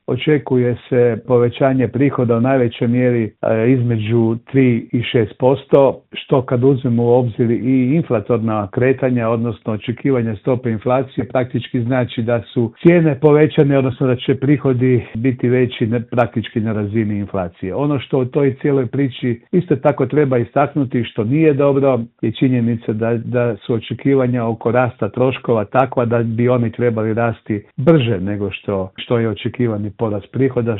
u telefonskom Intervjuu Media servisa naglašava da možemo biti optimistični, ali oprezni.